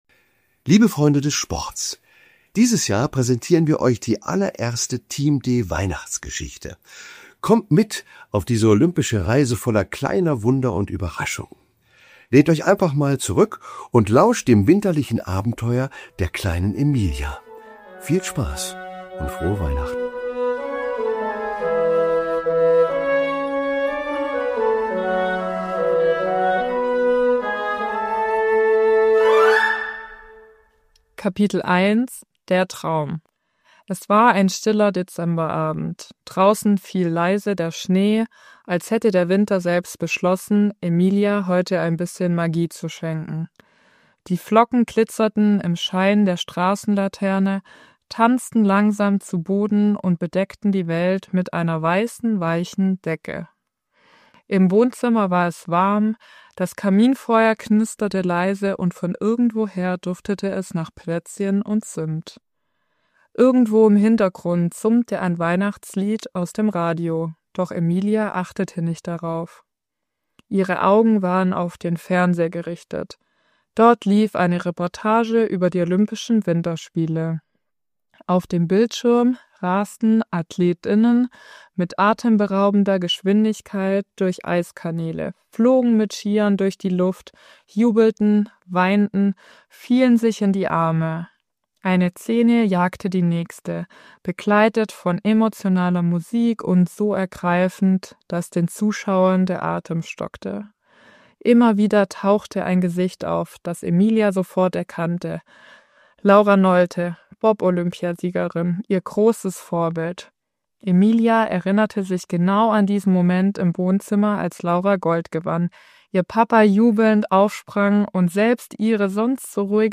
Die Team D Weihnachtsgeschichte in fünf Kapiteln gelesen von Anna-Maria Wagner, Lisa Mayer, Joshua Abuaku und Carsten Sostmeier. Taucht mit uns ein, wenn die kleine Elena auf den Spuren von Laura Nolte ihre Träume verfolgt.